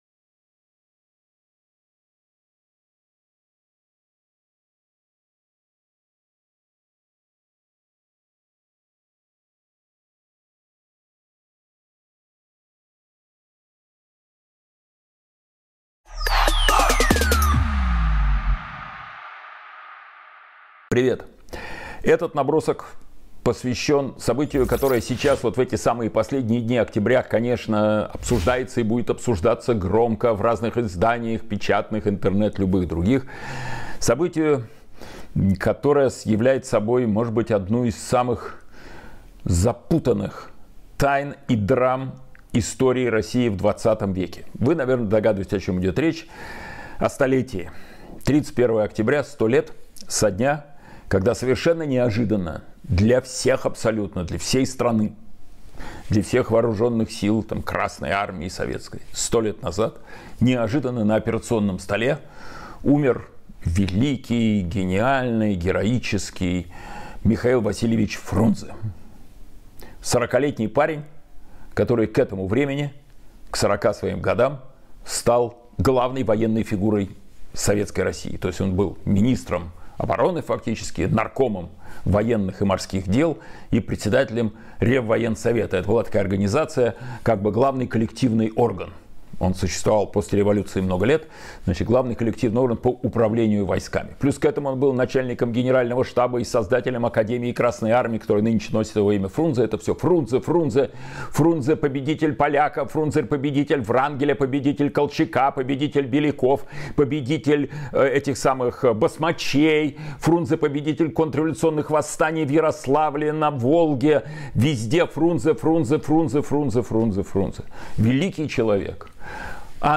Эфир ведёт Кирилл Набутов